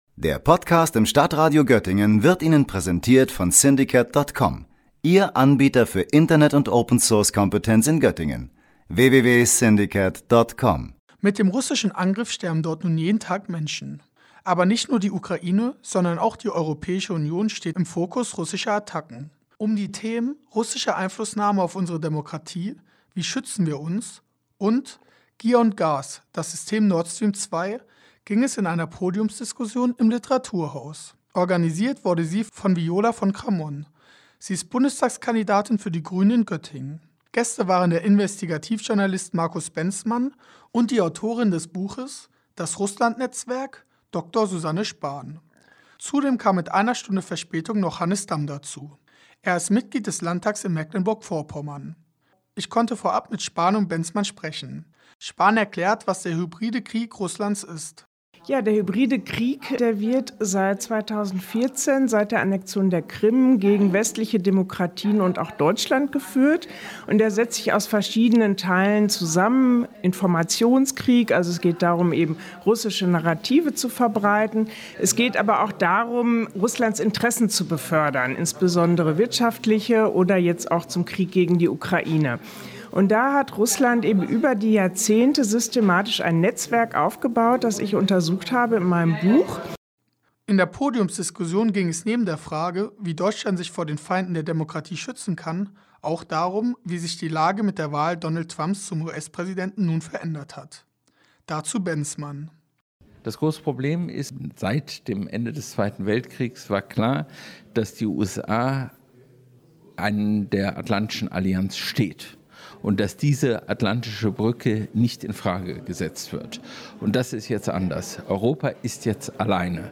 Russland führt einen hybriden Krieg gegen die EU. Dies war die einhellige Meinung bei einer Podiumsdiskussion zu Nord Stream 2 und die russische Einflussnahme auf unsere Demokratie.